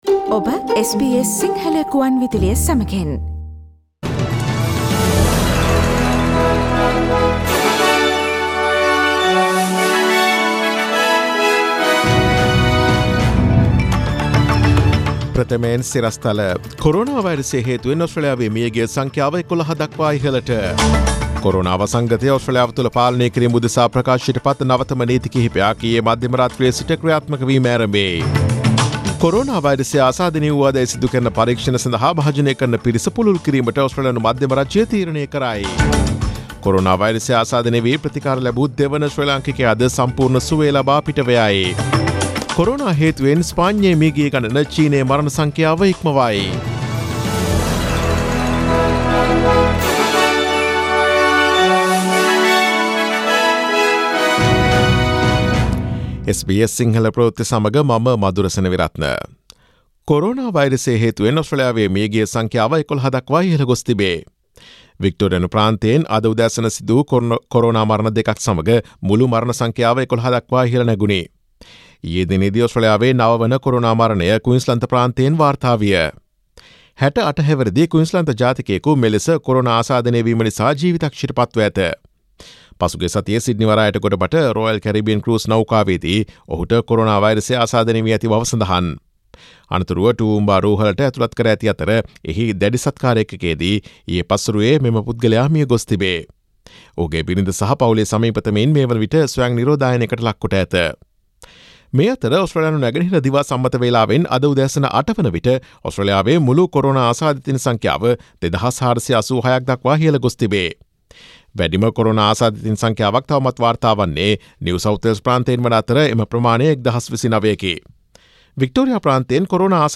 Daily News bulletin of SBS Sinhala Service: Thursday 26 March 2020